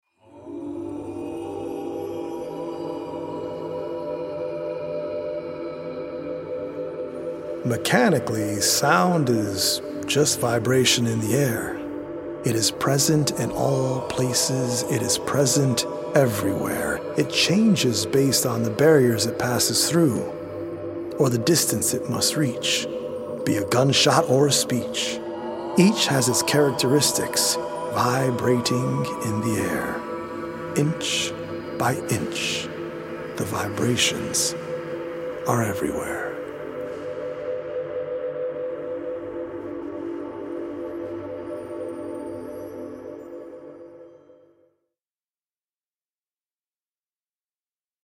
poetic recordings
healing Solfeggio frequency music